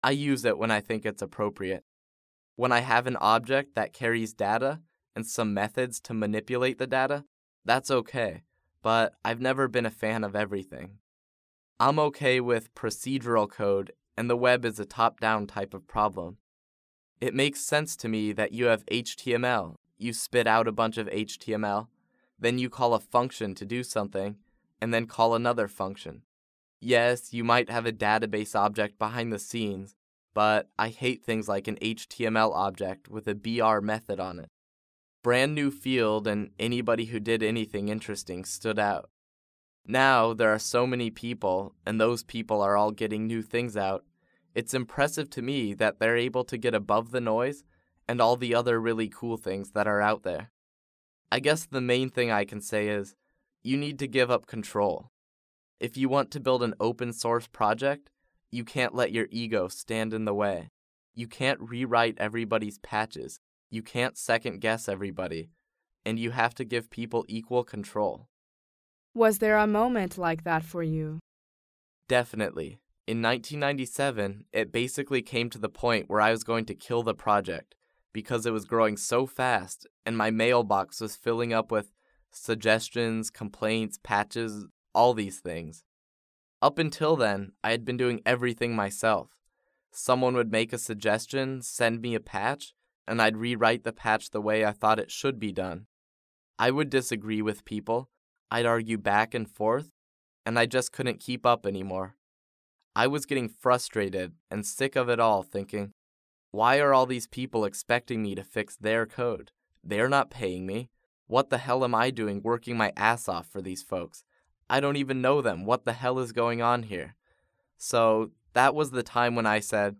在线英语听力室创业成功人士访谈录 第58期:PHP团队没有任何结构(3)的听力文件下载, 《深度对话:创业成功人士访谈录》精选了世界范围内有名的成创业人士，文本中英对照，配以MP3与对应字幕，培养您用英文思维的能力，真正做到学以致用。